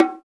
Index of /90_sSampleCDs/Roland - Rhythm Section/PRC_Latin 1/PRC_Conga+Bongo